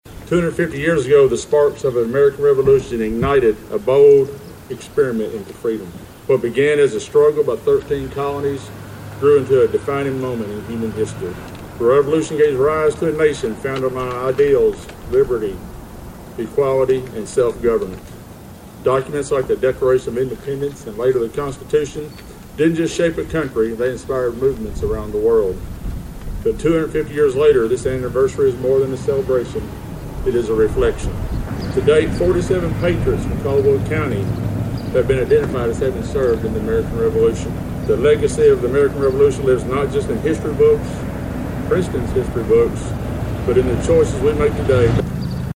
The ceremony was held on the front lawn of the Caldwell County Courthouse with a good attendance of residents.